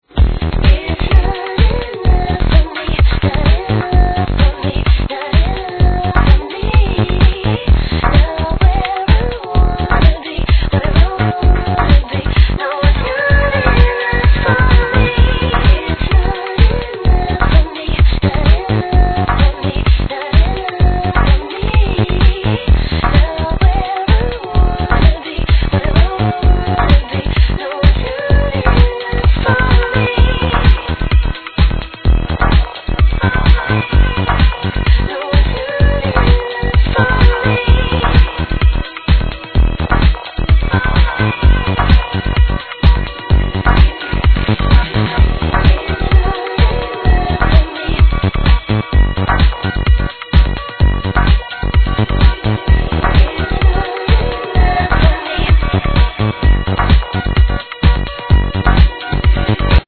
Nice vocal electro
Really good vocal electro tune.